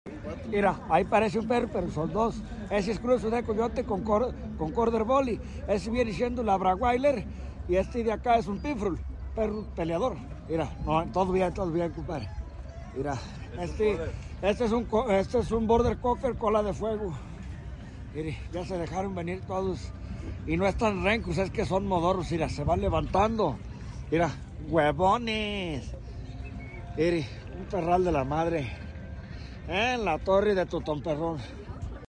Corgis bebés sound effects free download